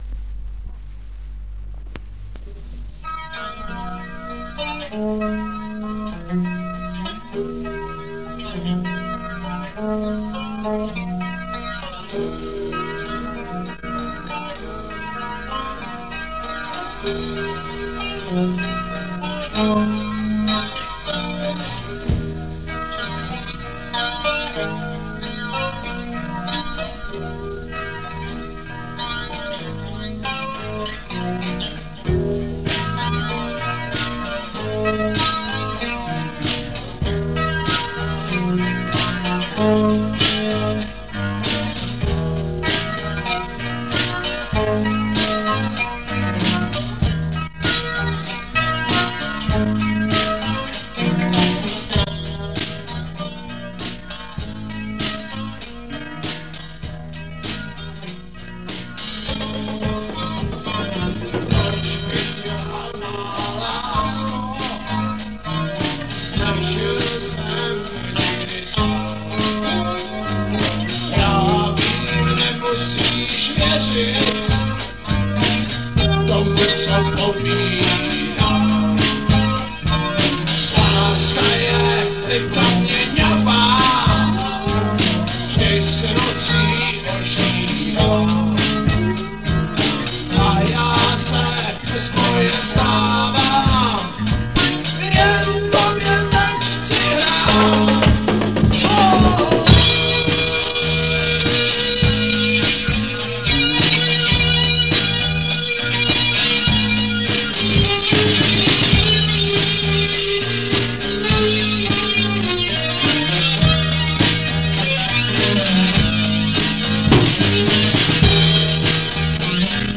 Anotace: ...jako doprovod demo - písnička co jsme kdysi nahráli s kapelou "PVC-rock" :-)
připomnělo mi dětství, zábavky, kamarády, co měli kapely, zkušebny, syrovost
opravdovost a pohodu rytmu